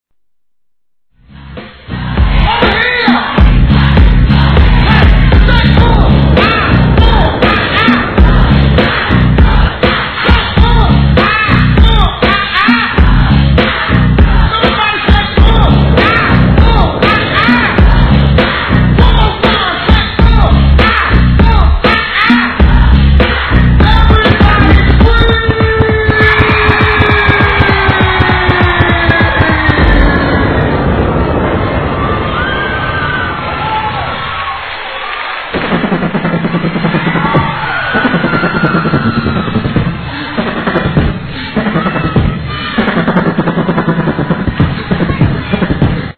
HIP HOP/R&B
お得意のヒューマンビート、コール＆レスポンス有りの部屋に居ながらにしてライブの一体感を体感できる優れもの！